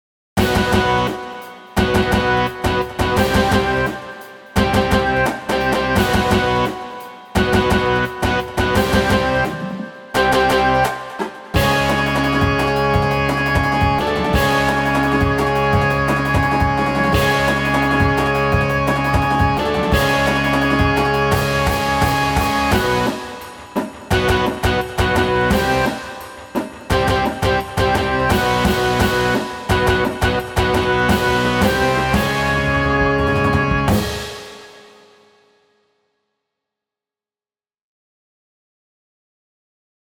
• Flute
• Clarinet
• Alto Sax
• Horn
• Trumpet
• Trombone
• Tuba
• Snare
• Bass Drums
• Bass Guitar
• Electric Guitar
• Drum Set